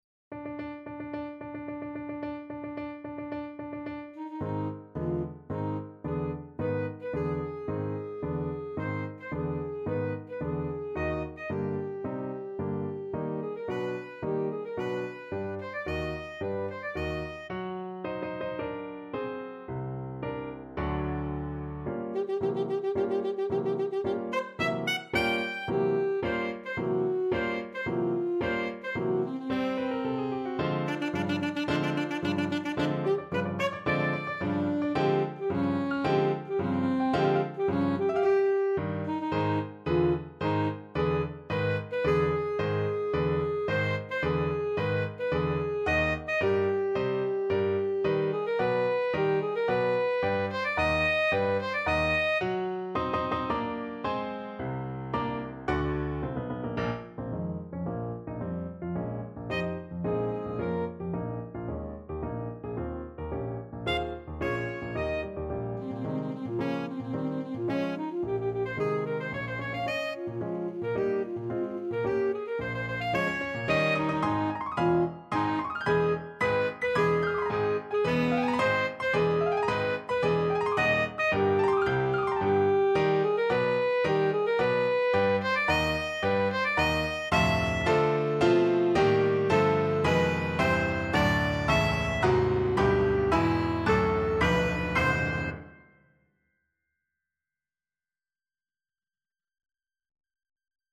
Alto Saxophone
~ = 110 Tempo di Marcia
4/4 (View more 4/4 Music)
B4-G6
Classical (View more Classical Saxophone Music)